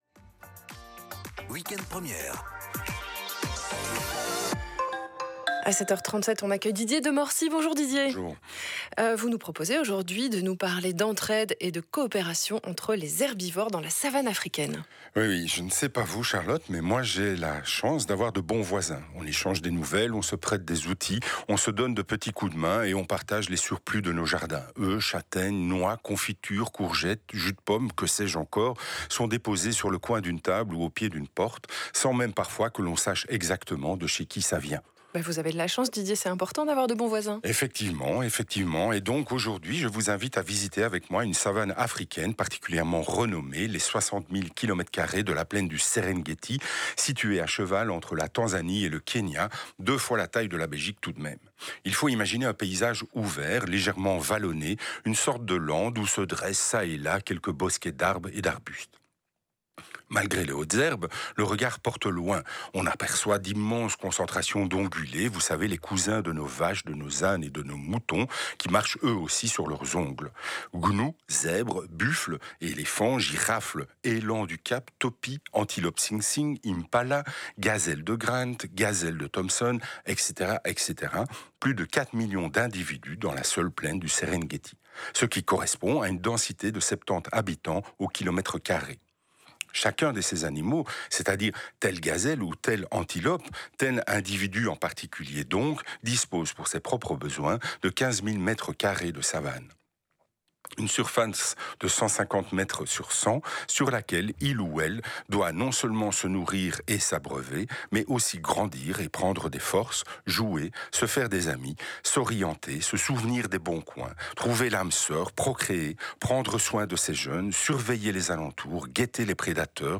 La chronique :